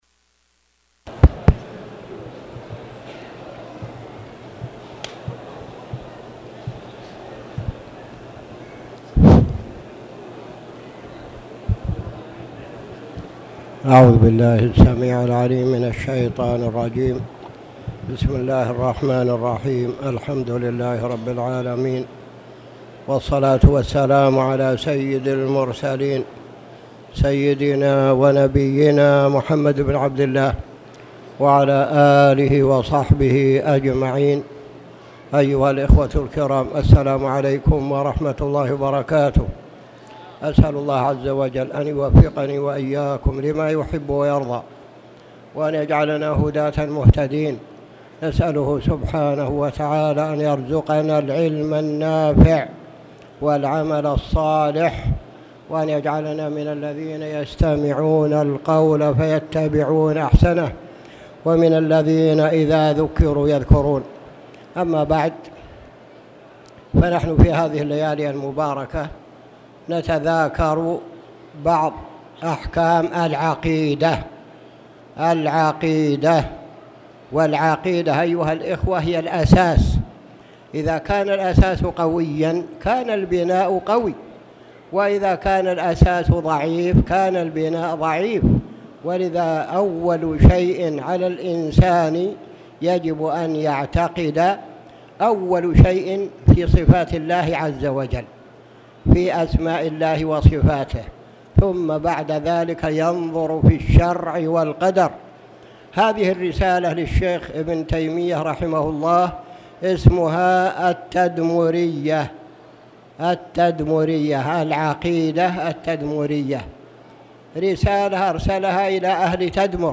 تاريخ النشر ١٢ صفر ١٤٣٨ هـ المكان: المسجد الحرام الشيخ